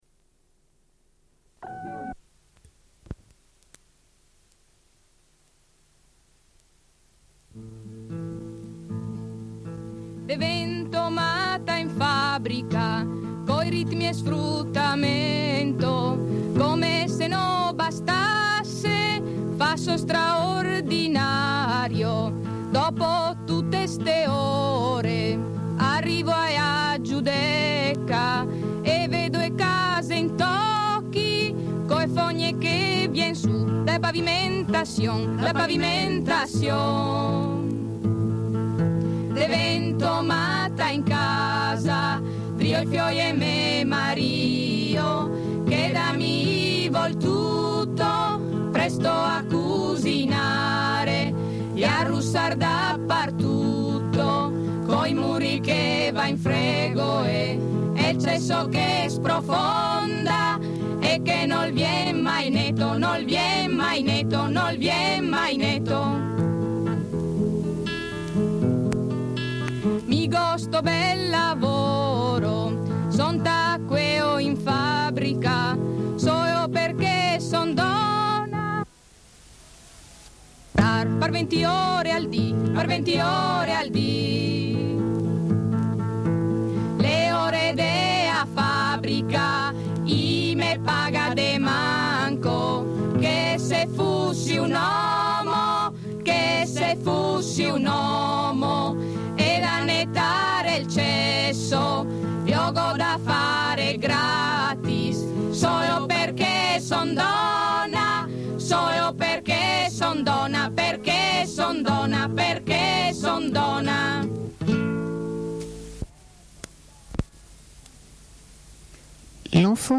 Une cassette audio, face A
Lecture d'un poème de Sonia Dorman tiré du livre Femmes et merveilles de Pamela Sargent.